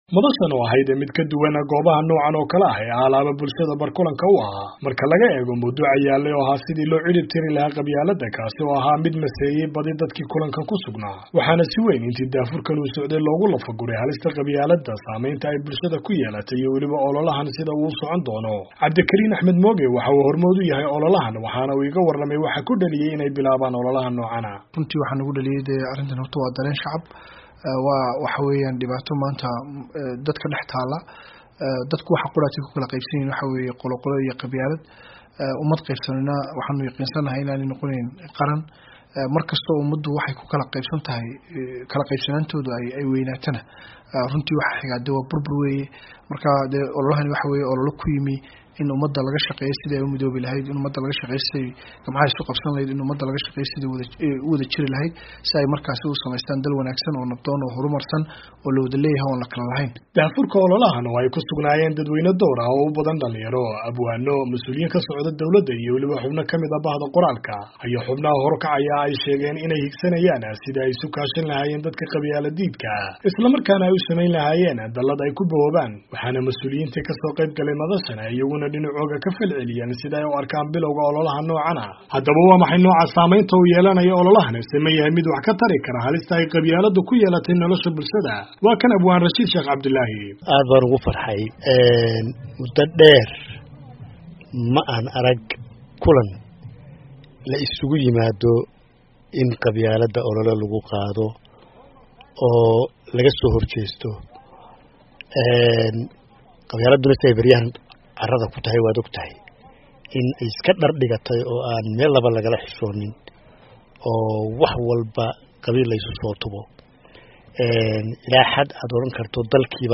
HARGEYSA —